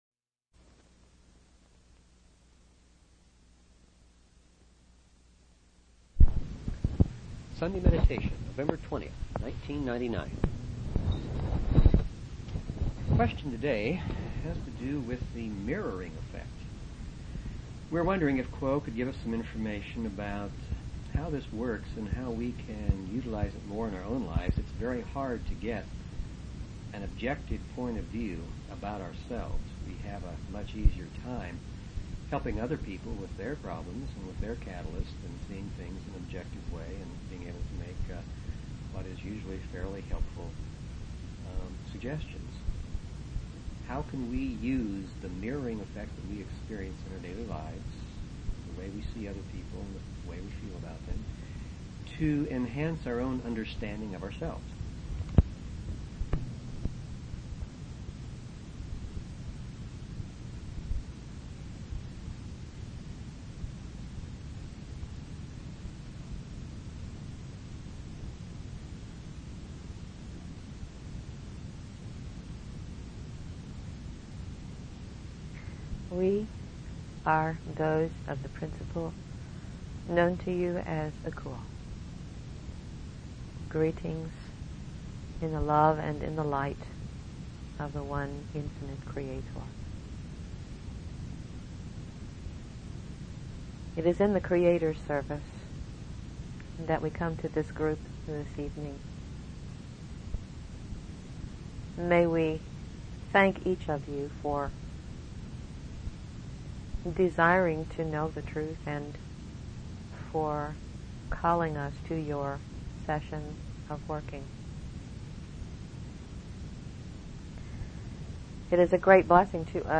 /llresearchquocommunications#archives Paranormal Philosophy Physics & Metaphysics Spiritual Medium & Channeling 0 Following Login to follow this talk show LL Research Quo Communications